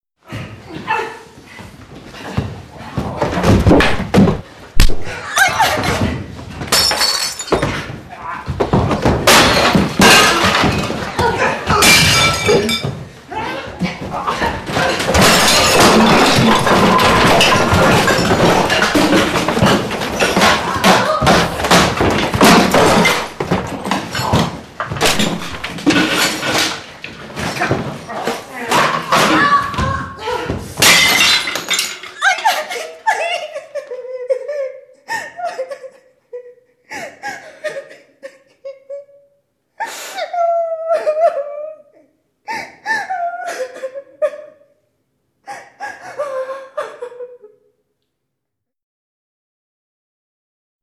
Прикольные звонки